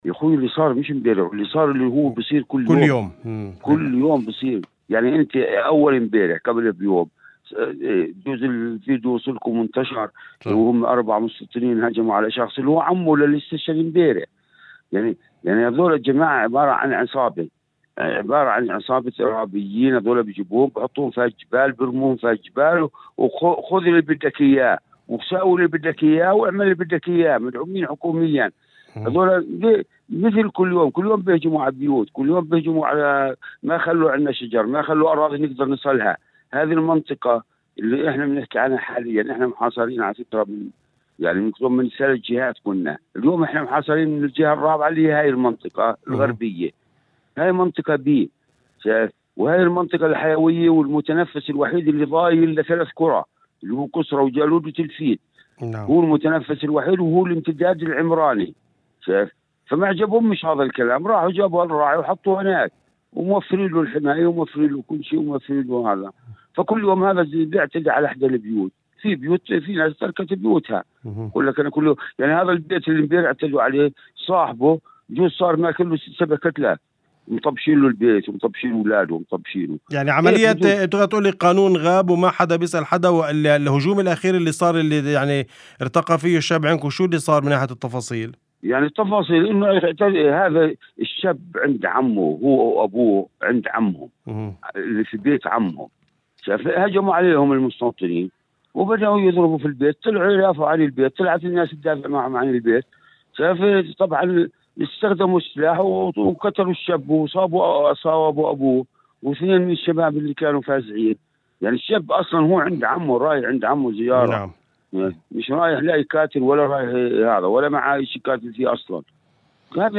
وأضاف في مداخلة هاتفية ضمن برنامج "أول خبر" ، على إذاعة الشمس، أن المصابين نقلوا في البداية إلى مركز طبي داخل البلدة لتلقي العلاج، قبل نقل بعض الحالات إلى مستشفى رفيديا الحكومي في نابلس.